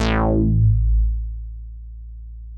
MICROMOOG A2.wav